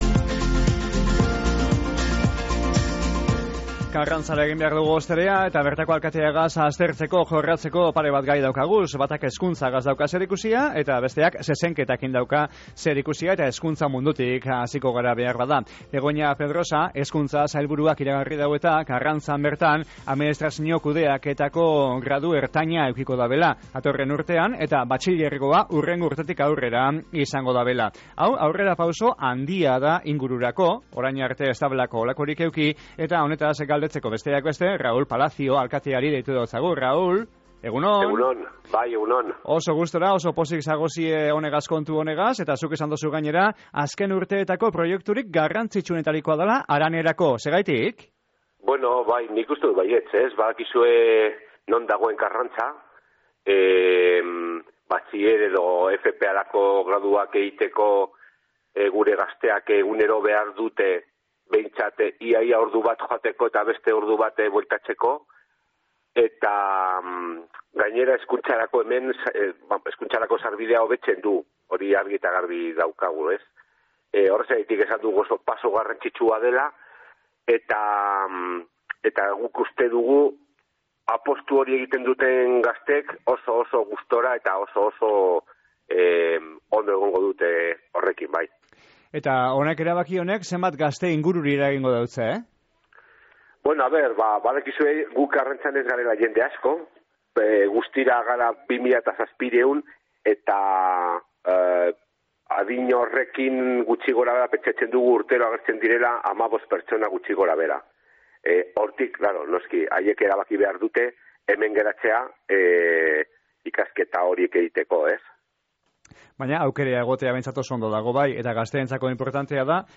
“2013ko galdeketan parte hartu ez eben gazte asko dagoz orain eta euren eretxia emon nahi dabe” esan dau Raul Palaciosek, Karrantzako alkateak Goizeko Izarretan saioan.